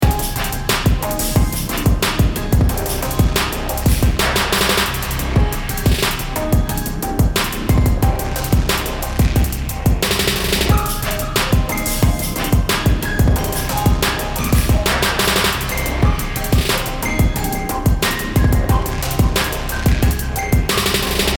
528hz BPM90-99 calm Game Instrument Soundtrack インストルメント 穏やか
BPM 90